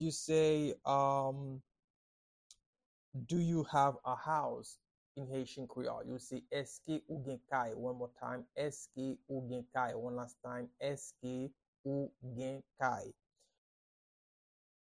Pronunciation and Transcript:
Do-you-have-a-house-in-Haitian-Creole-–-Eske-ou-gen-kay-pronunciation-by-a-Haitian-teacher-1.mp3